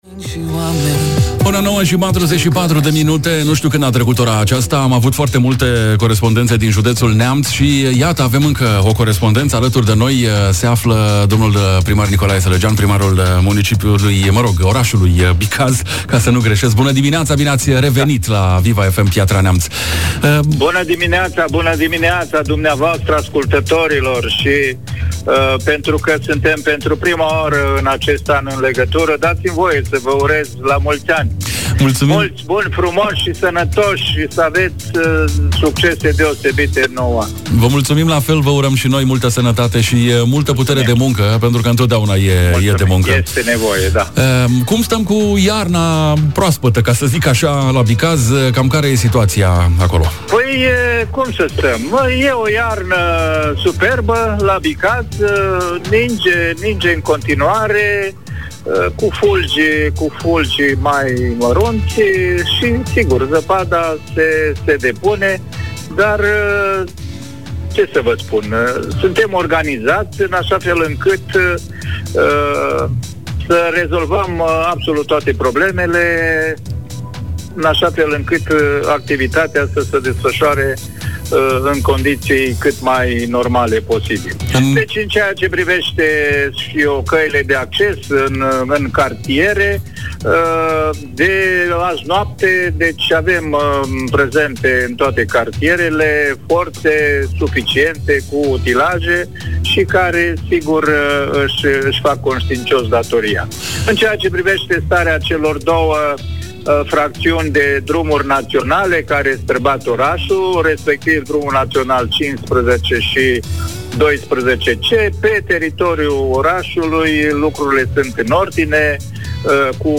În urma căderilor de zăpada din județul Neamț, au apărut și situațiile care necesită intervenția autorităților. Viva FM Piatra Neamț s-a aflat în legătură telefonică directă cu primarii celor mai importante orașe din județ.
4-Nicolae-Salagean-Primar-Bicaz.mp3